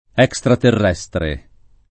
vai all'elenco alfabetico delle voci ingrandisci il carattere 100% rimpicciolisci il carattere stampa invia tramite posta elettronica codividi su Facebook extraterrestre [ H k S traterr $S tre ] o estraterrestre agg. e s. m.